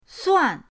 suan4.mp3